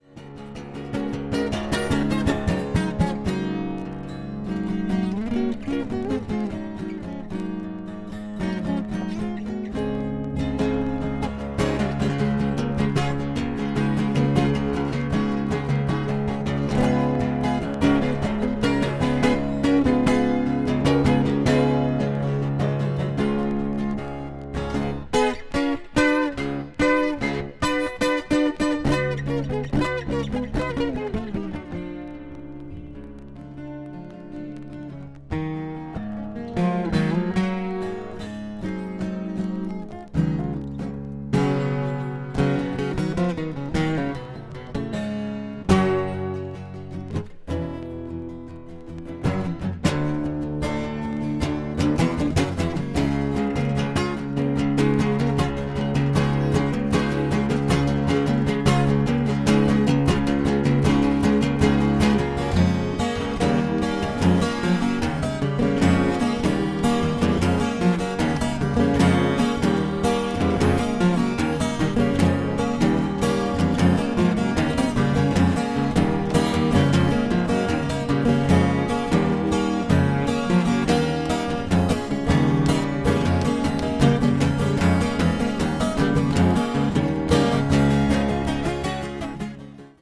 (わずかにチリノイズが入る箇所あり)
ソロとはにわかに信じがたい多重録音のようなギター・ソロを披露しています。